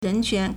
人权 (人權) rénquán
ren2quan2.mp3